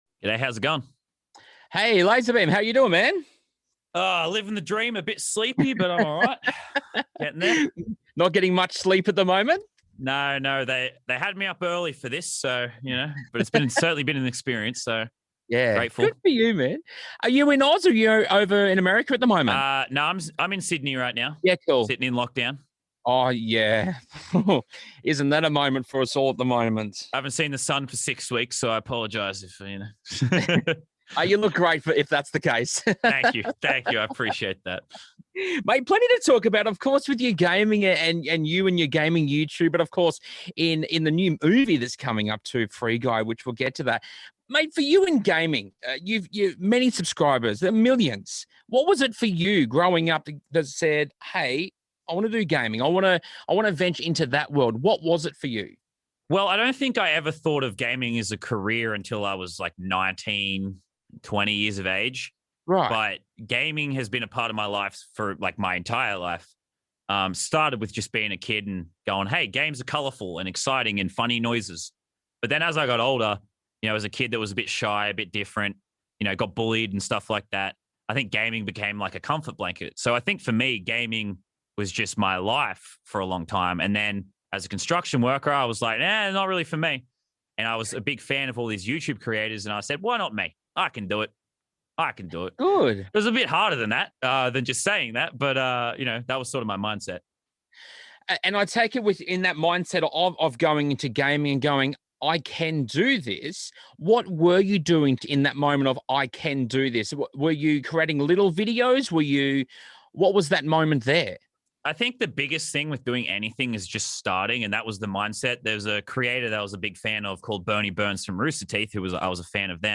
LazerBeam Interview